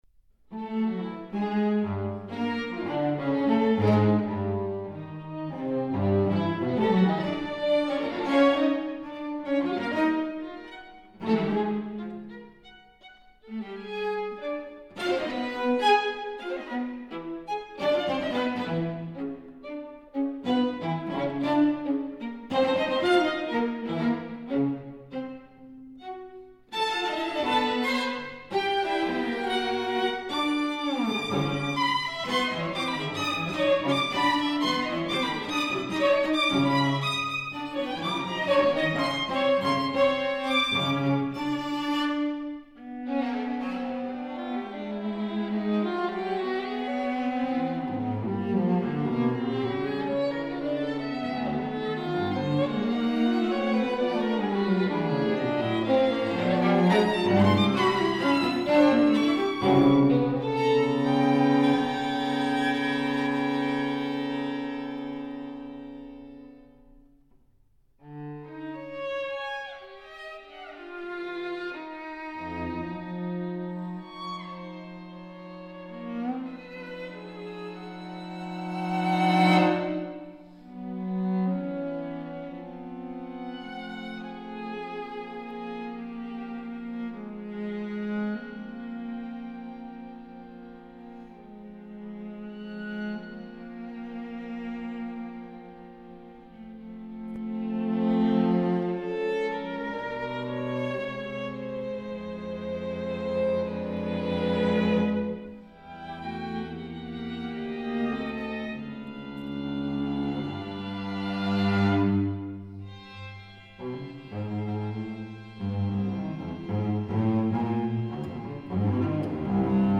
LIGHT RHYTHMIC (3:33)